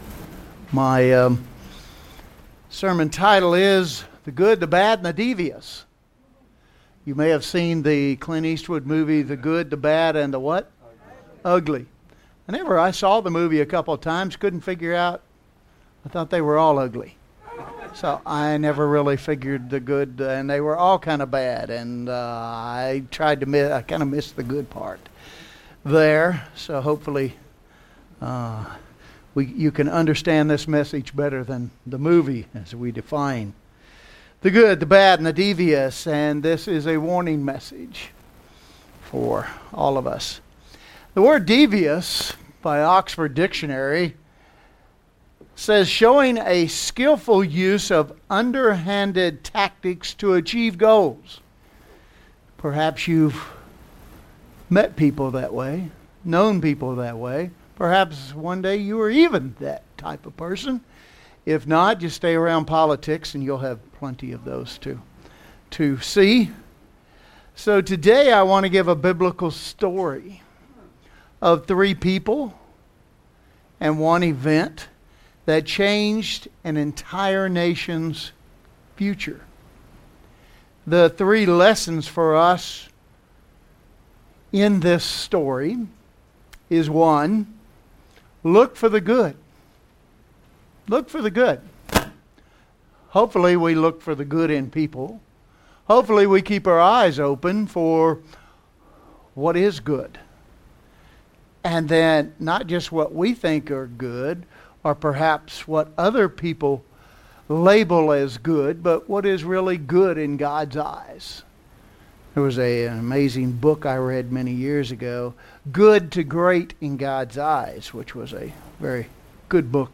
The Good, the Bad and the Devious | United Church of God